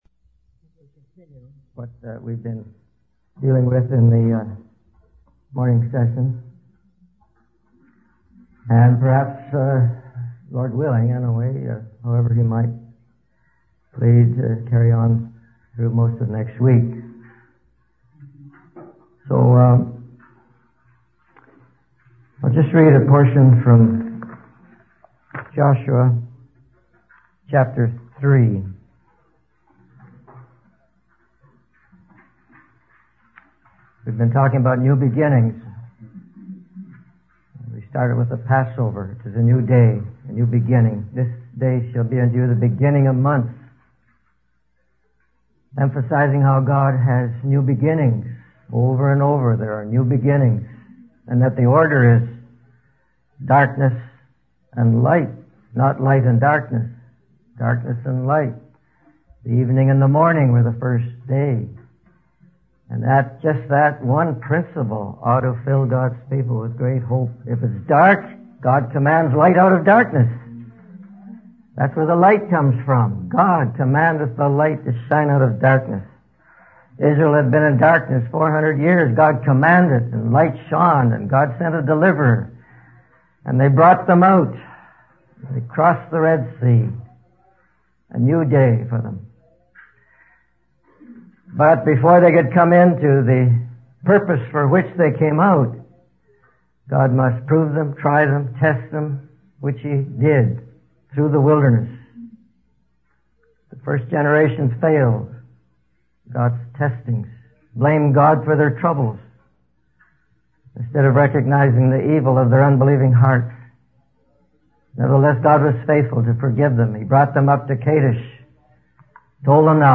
In this sermon, the speaker discusses the concept of new beginnings and how God brings light out of darkness. He references the story of the Israelites in the wilderness, where the old generation failed God and a new generation emerged. The speaker emphasizes the importance of being prepared and not failing when God presents an opportunity for a new way.